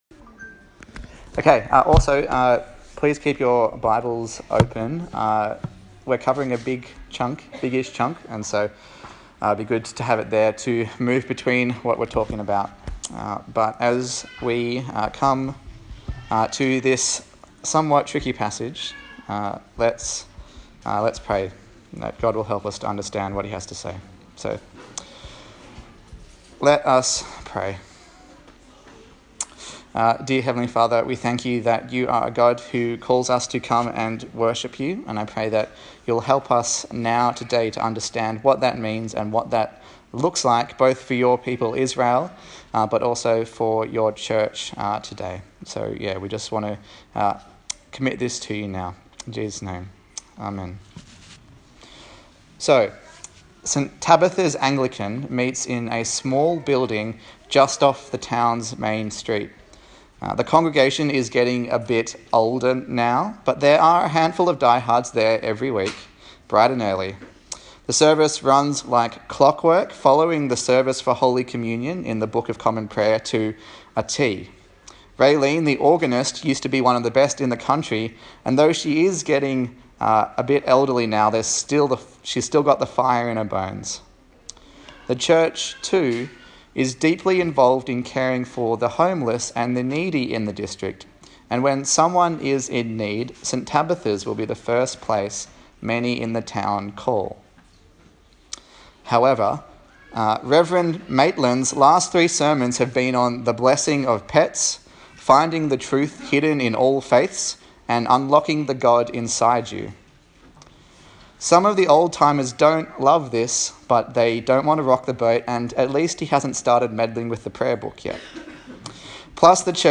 27/06/2021 Right Worship Preacher